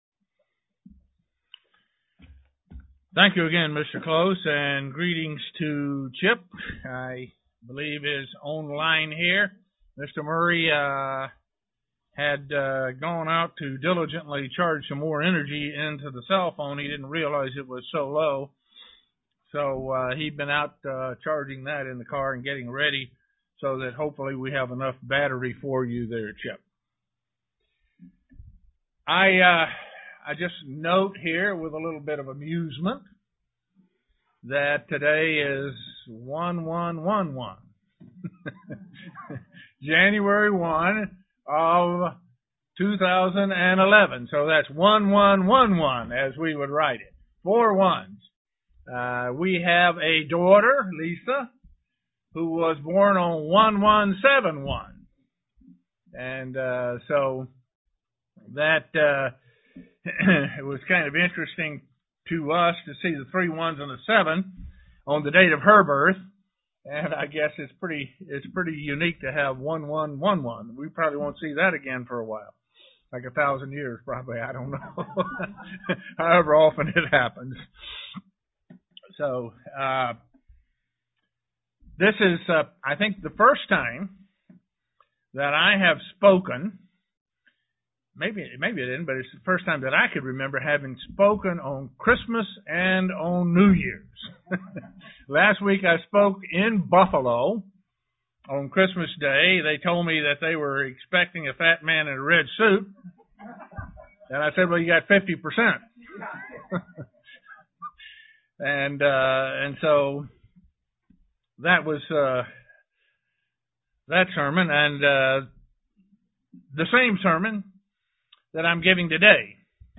Sermon explains how to be sanctified and how to tell if you are.